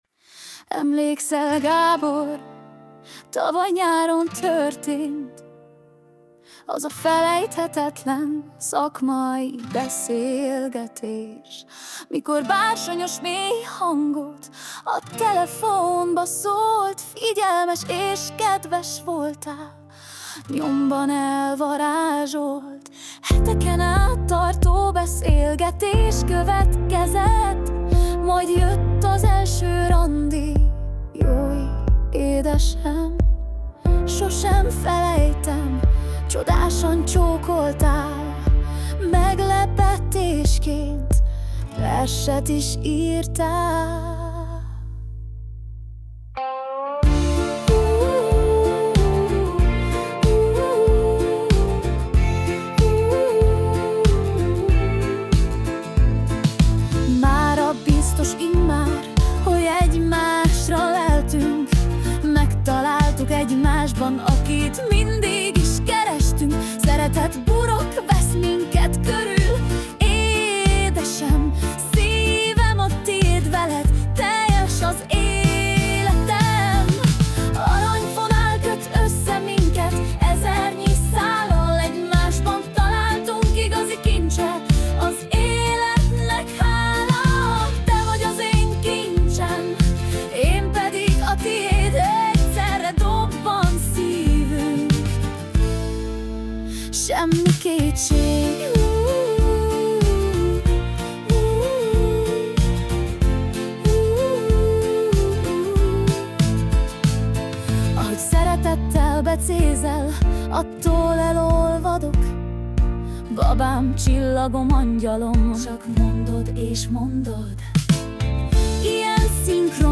Romantikus ajándék dal